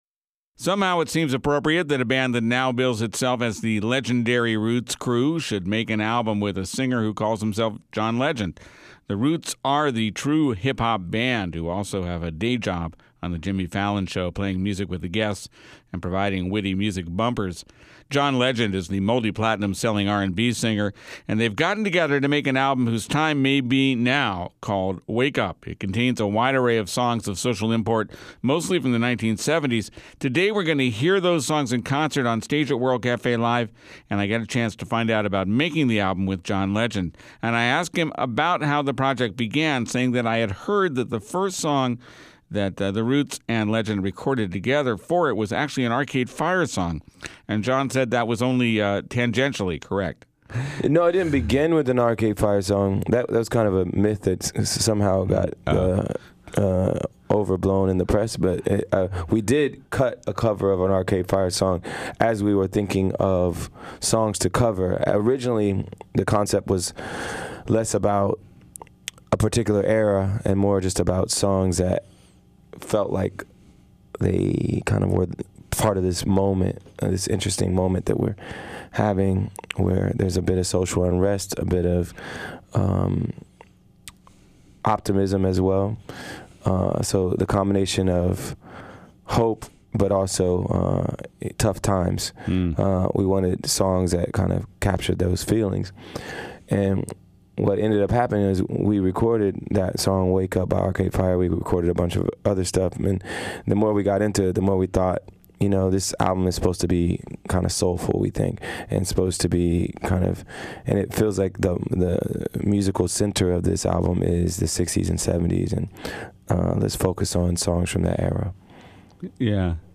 The neo-soul singer
soul covers
live from the Philadelphia studios